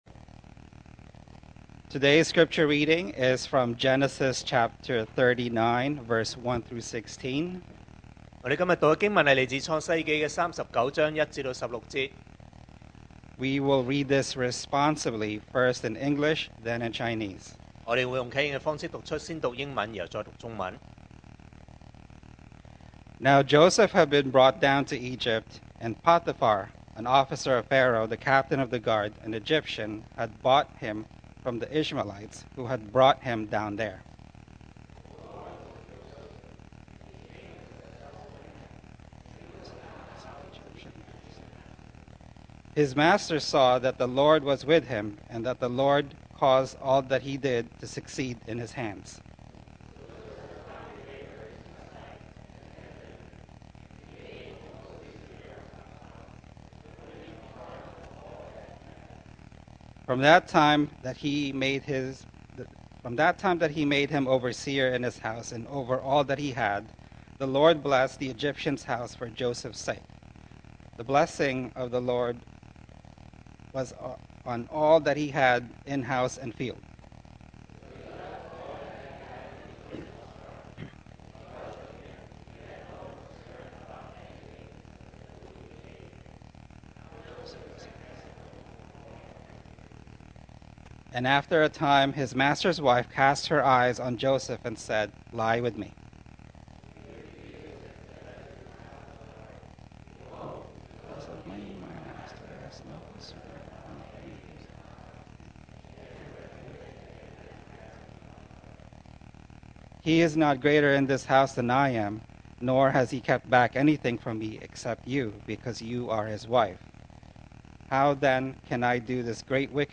2025 sermon audios 2025年講道重溫 Passage: Genesis 39:1-16 Service Type: Sunday Morning Are you serious?